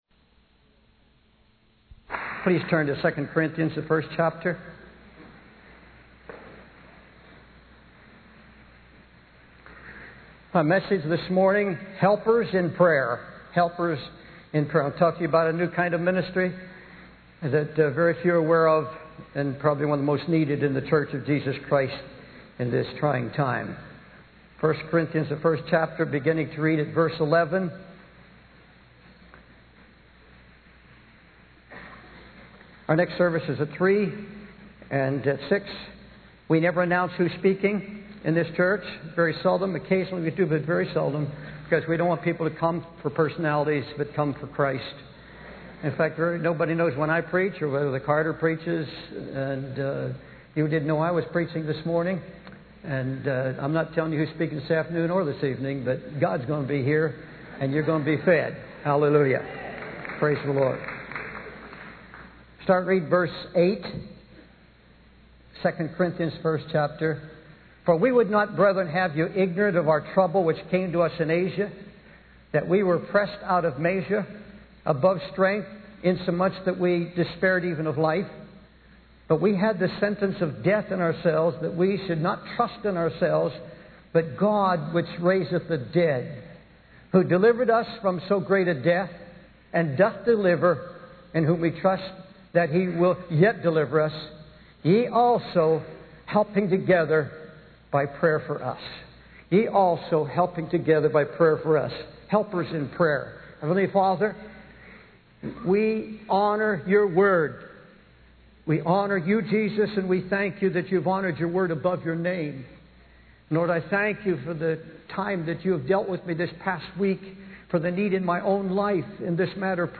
Helpers in Prayer by David Wilkerson | SermonIndex